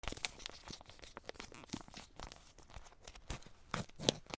レトルトパウチをあける